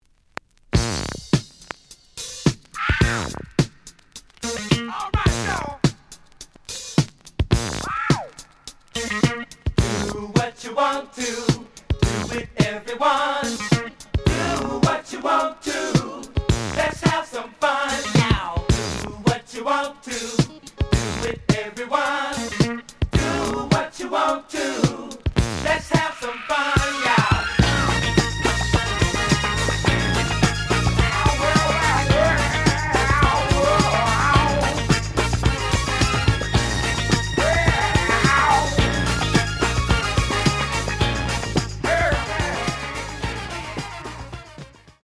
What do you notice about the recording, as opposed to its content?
The audio sample is recorded from the actual item. Edge warp. But doesn't affect playing. Plays good.